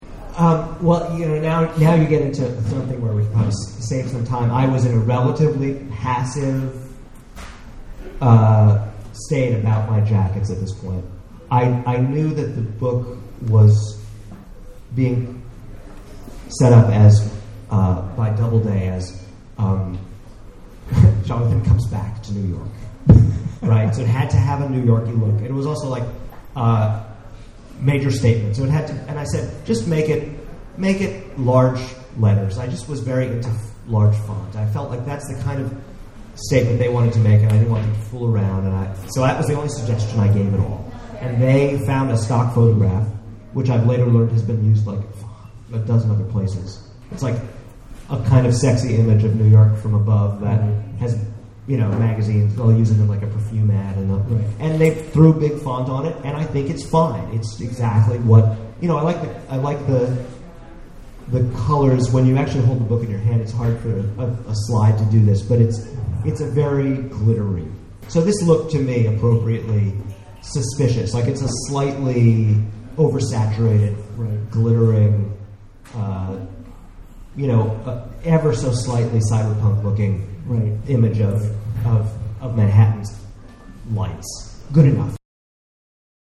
at The Last Bookstore in downtown Los Angeles for an in-depth discussion of his book covers.